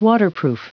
Prononciation du mot waterproof en anglais (fichier audio)
waterproof.wav